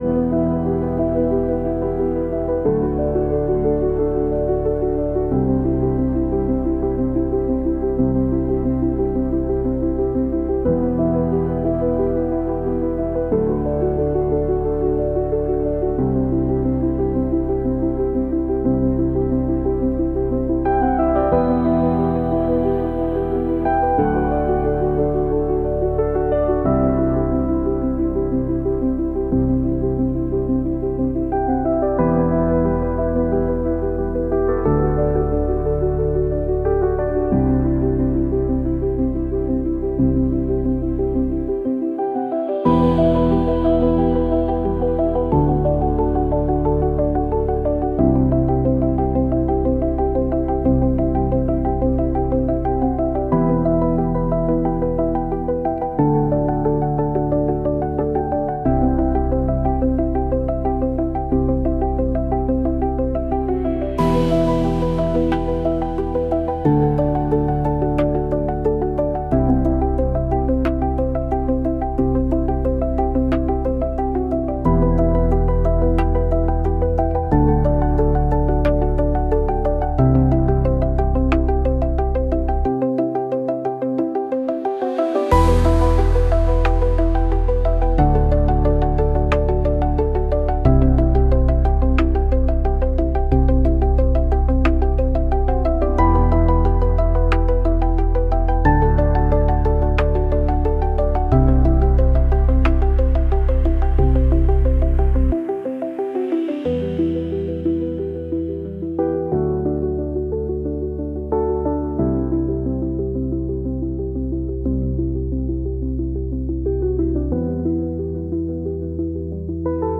a music for games